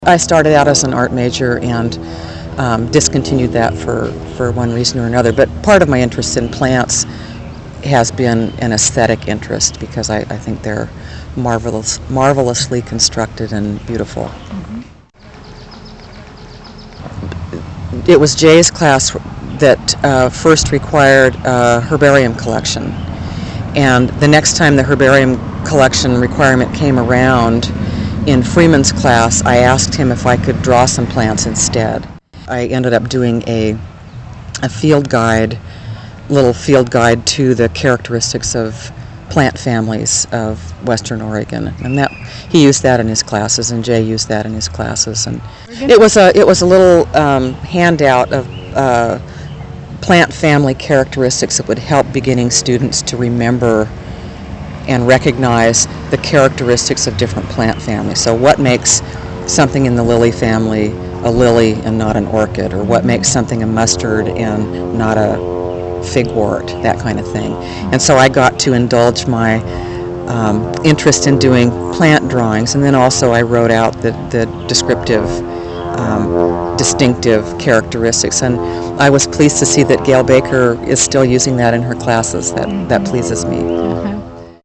Summary of Interview
Location: Mt. Pisgah Arboretum, Eugene, Oregon